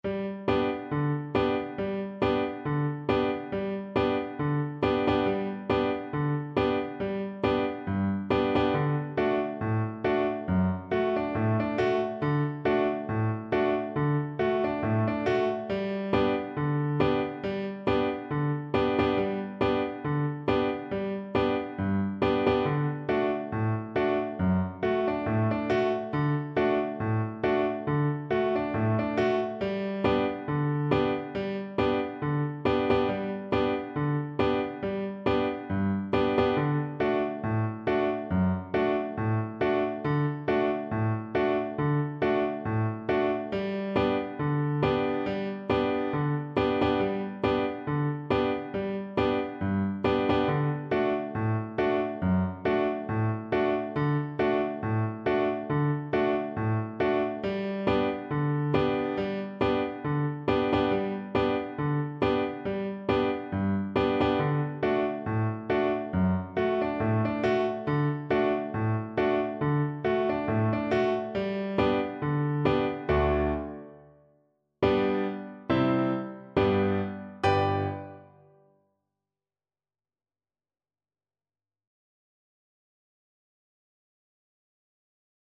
Violin
Traditional Music of unknown author.
G major (Sounding Pitch) (View more G major Music for Violin )
Two in a bar with a light swing =c.100